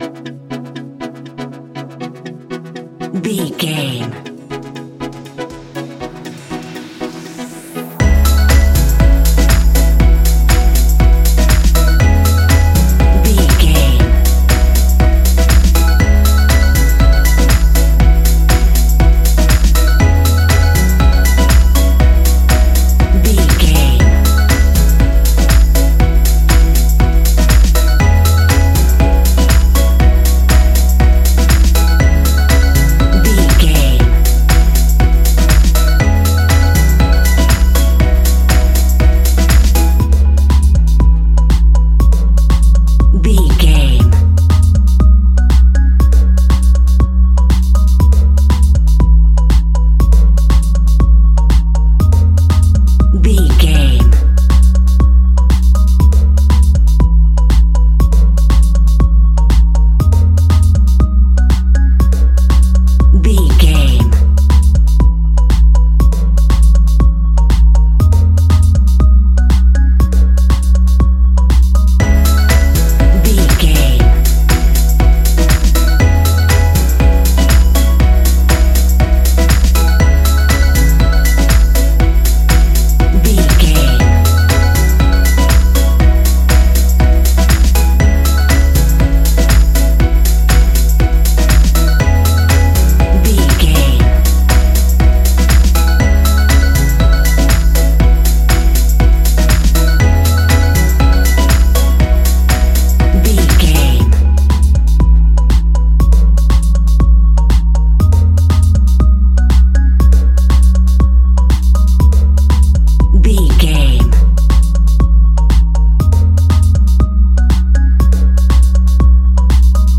Ionian/Major
A♭
house
electro dance
synths
techno
trance